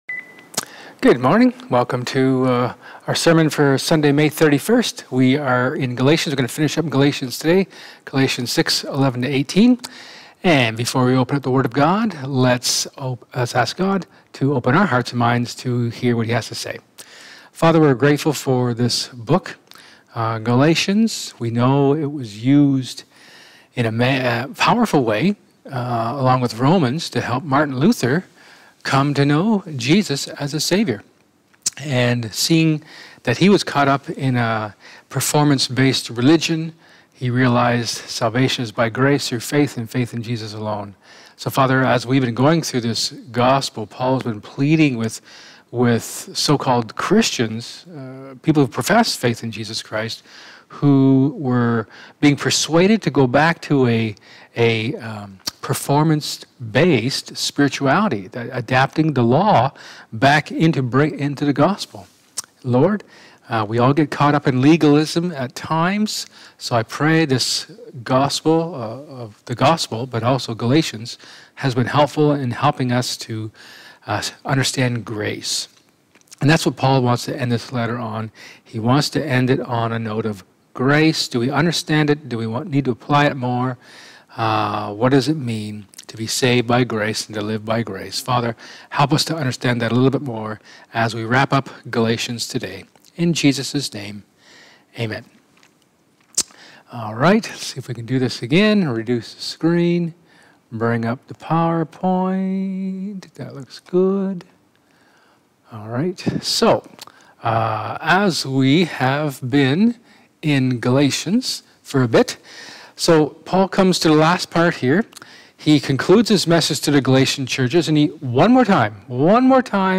Acts 17:1-15 Service Type: Sermon